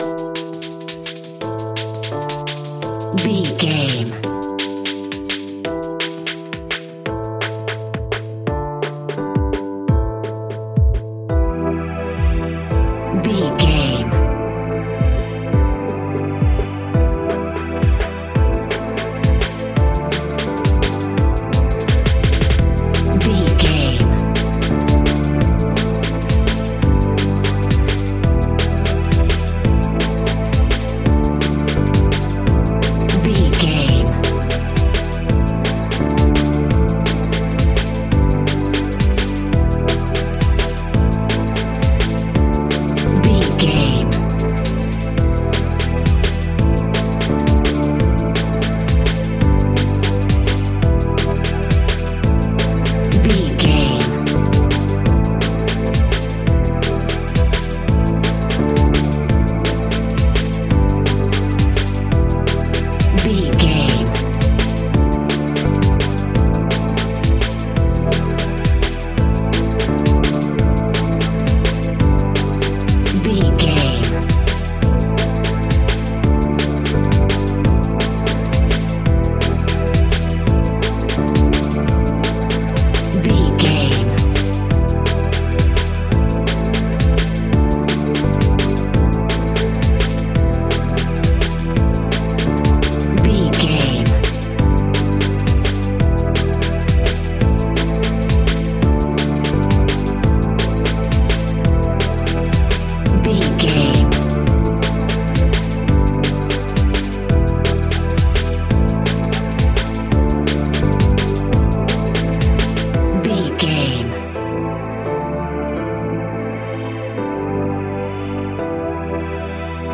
Aeolian/Minor
Fast
frantic
driving
energetic
hypnotic
industrial
dark
drum machine
electric piano
synthesiser
electronic
sub bass
synth leads
synth bass